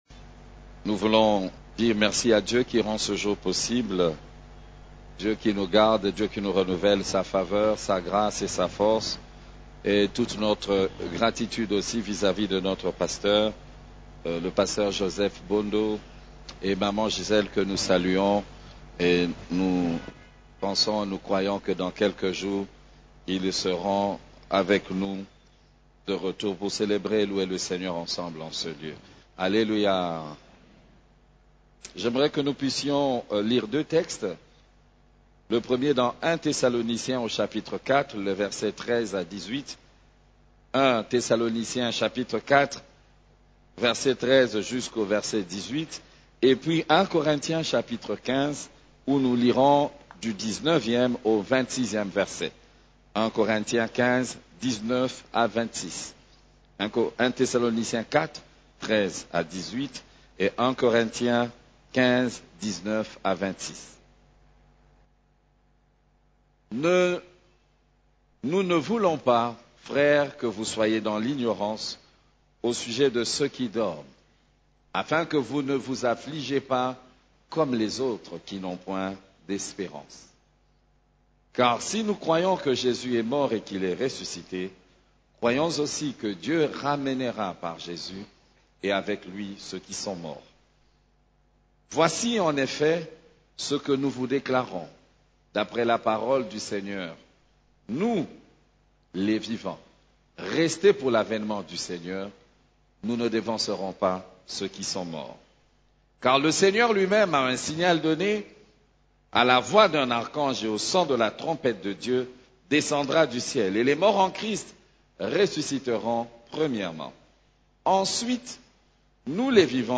CEF la Borne, Culte du Dimanche, L'enlèvement de l'église et le retour de Jésus-Christ